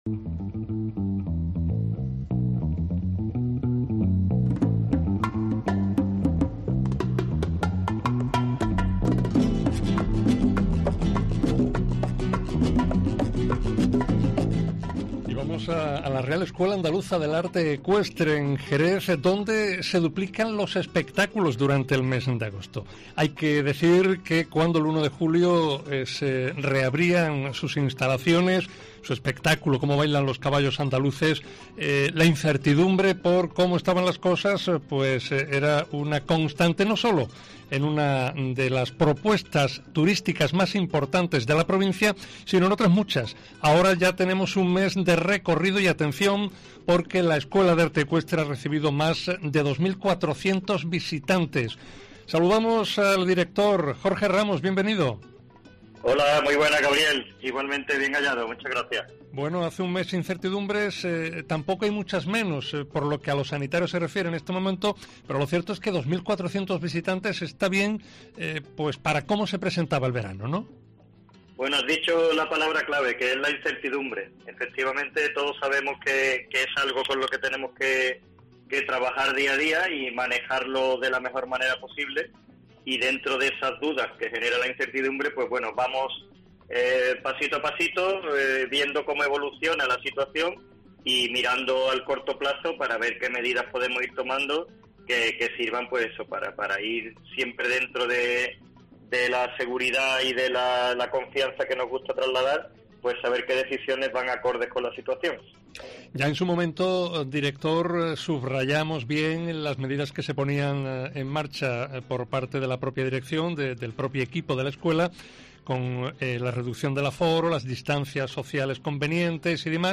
Entrevista para Mediodía Cope Provincia de Cádiz